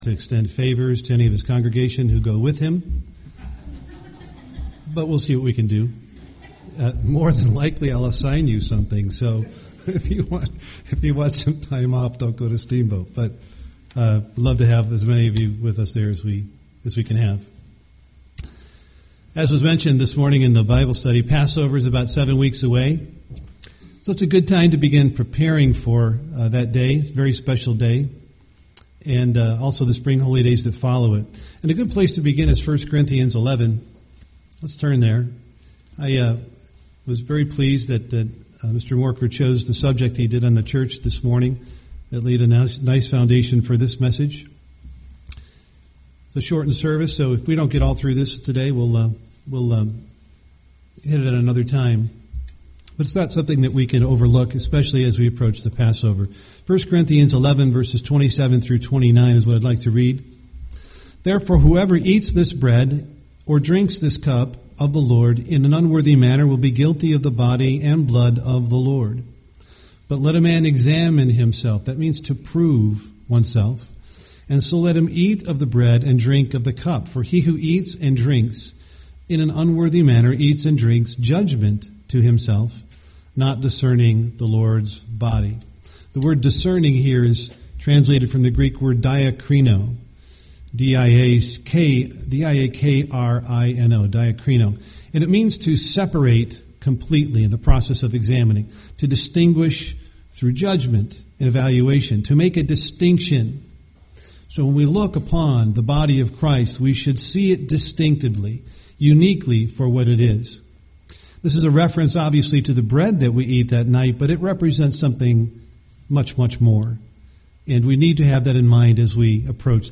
UCG Sermon Body of Christ Studying the bible?